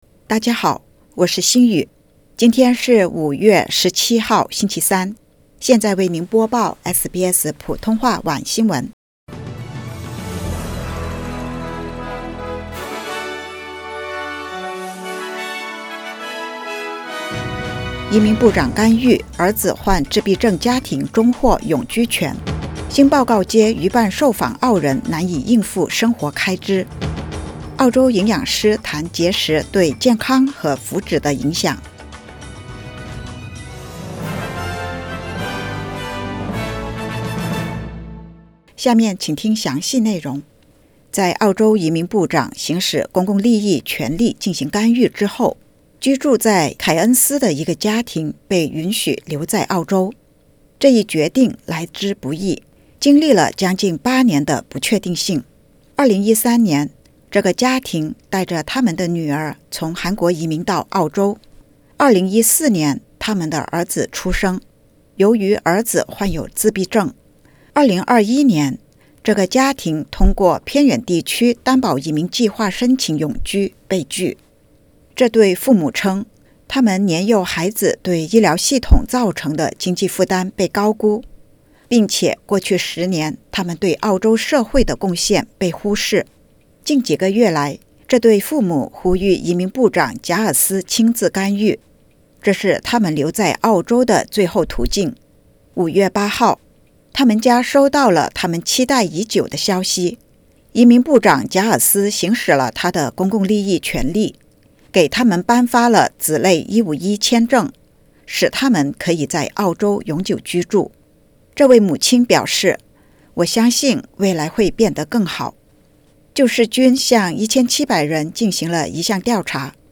SBS Mandarin evening news Source: Getty / Getty Images